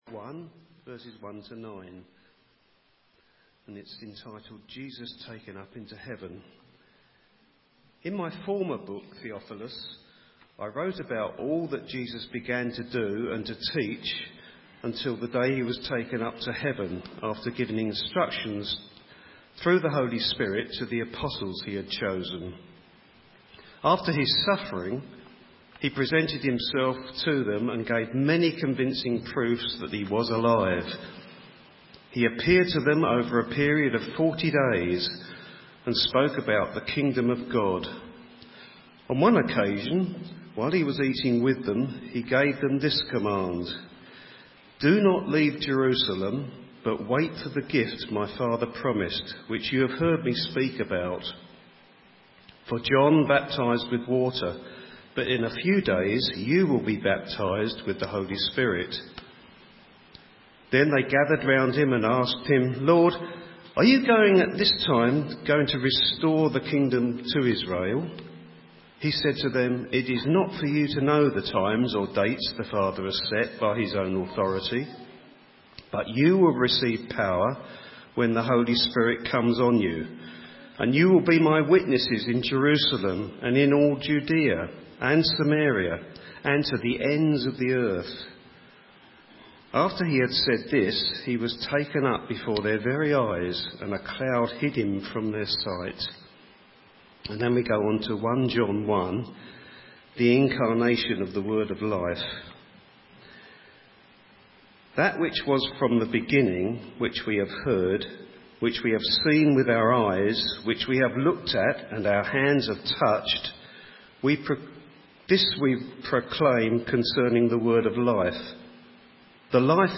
A message from the series "Mission Shift."